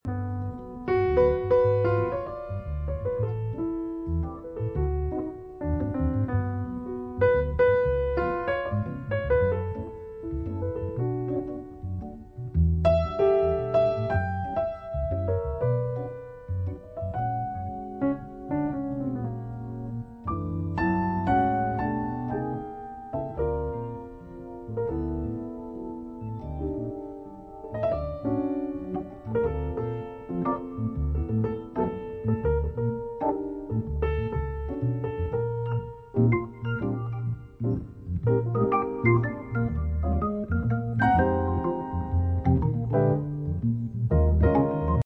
Il y a un swing là-dedans... fabuleux.